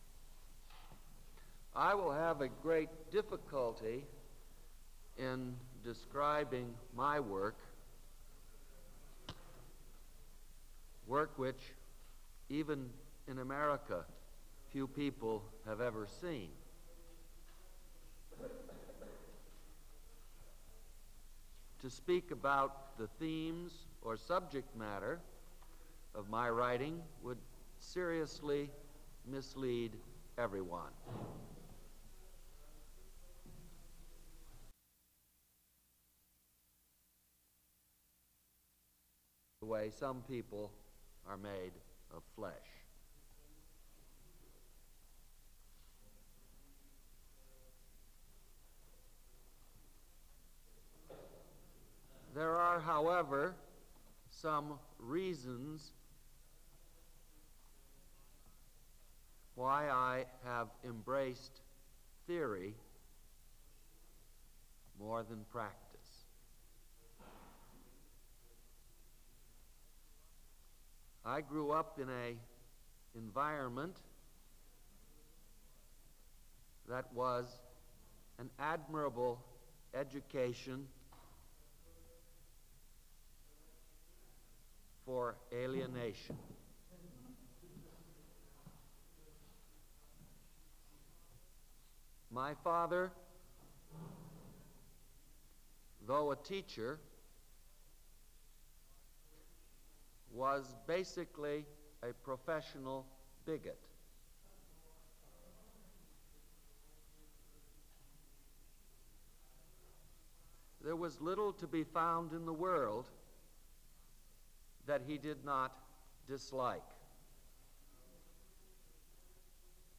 5a80f2e89c41ebf8e7f579d799a706d8985a542f.mp3 Title American/Russian Writers Conference, Tape 1 Part 2 Description In November 1985, American writers Louis Auchincloss, Charles Fuller, William Gaddis, William H. Gass, Allen Ginsberg, and Arthur Miller joined a conference with Soviet writers. On this tape (among 14 total), Gass gives an introduction to his biographical background and his approach to / outlook on writing.